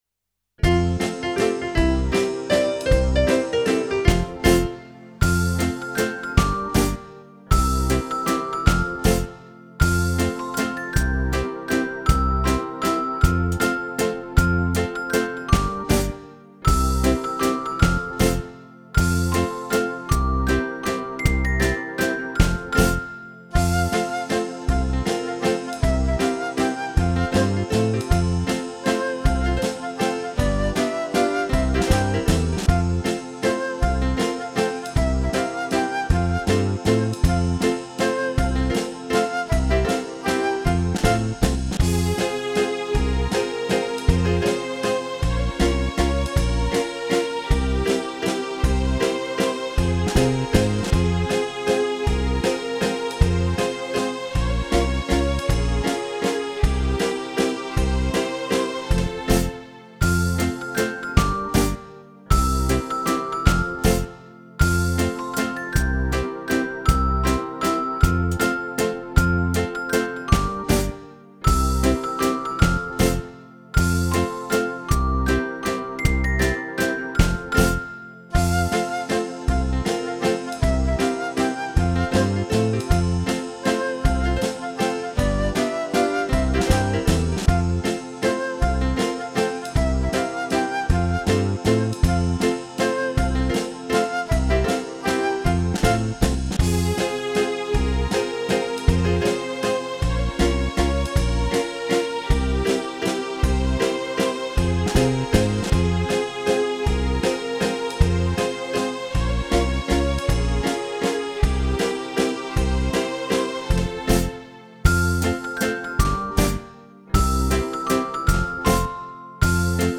Java